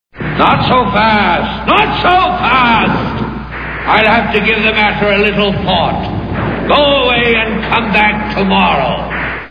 The Wizard of Oz Movie Sound Bites